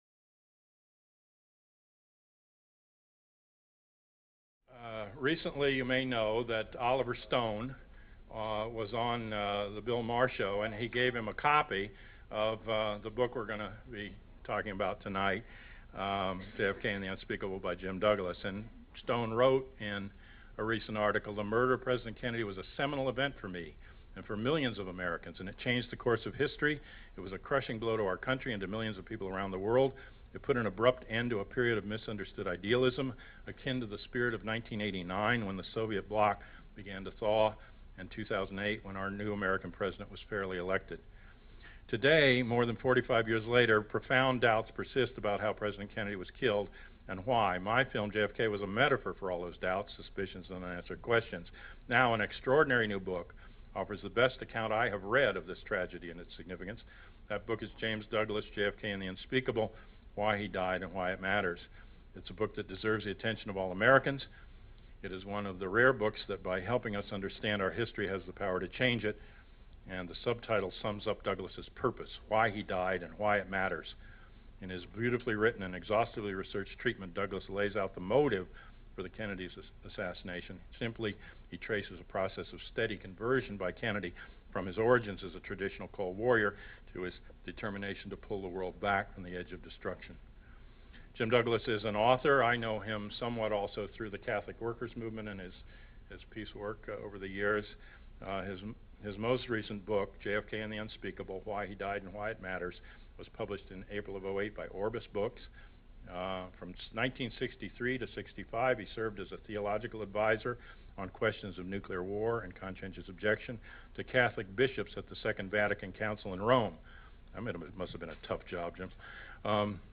Coalition on Political Assassinations Conference 20 November 2009 Dallas, Texas